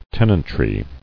[ten·ant·ry]